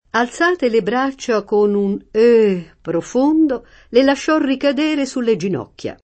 euh [ ö ] escl.